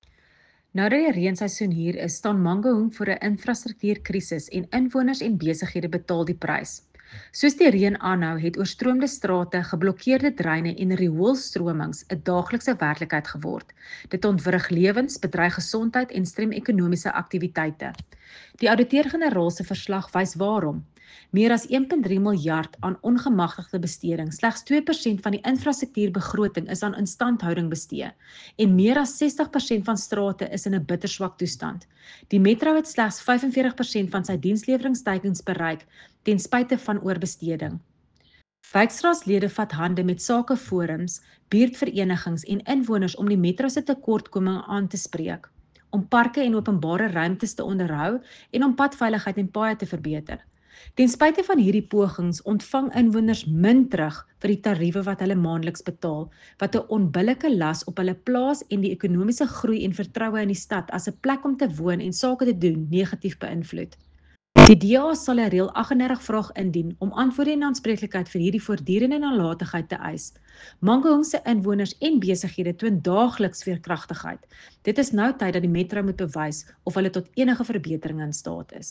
Afrikaans soundbites by Cllr Corize van Rensburg and